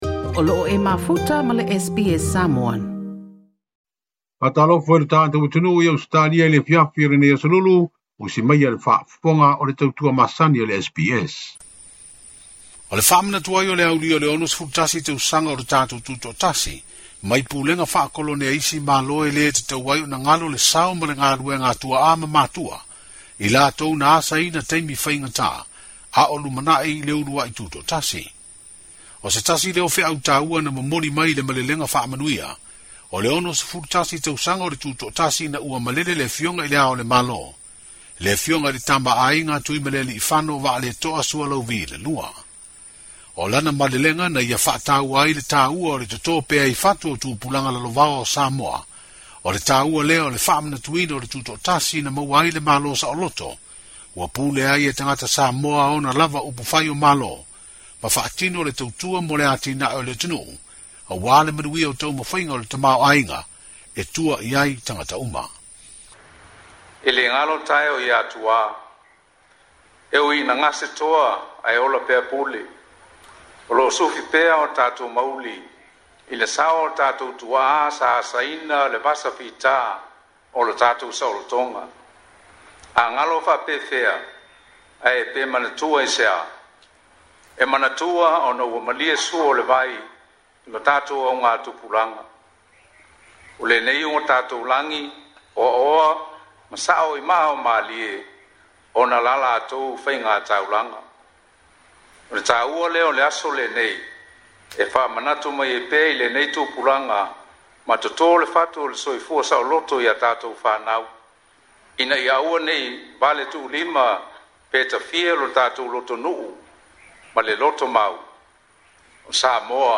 Se vaega o le malelega a le Ao o le malo Samoa i le sauniga o le sisiga fu'a e faamanatu ai le 61 tausaga o le malo tuto'atasi o Samoa.